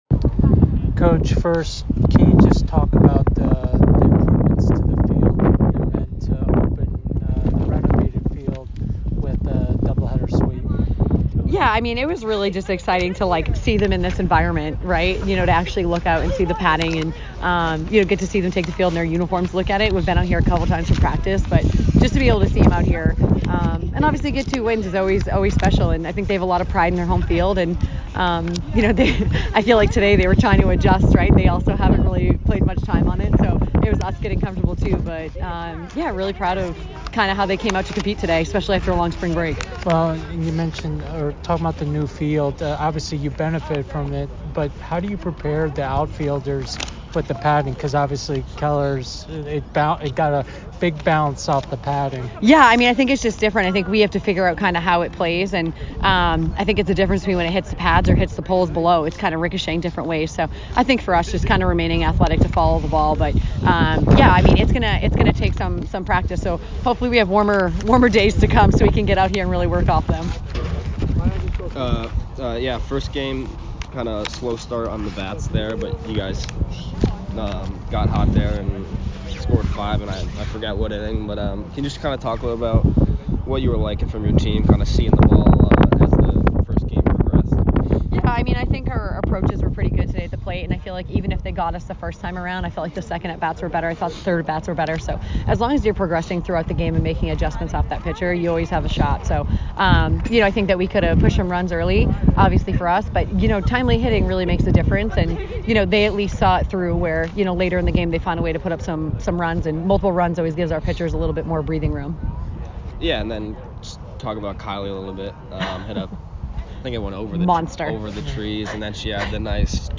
Yale DH Postgame Interview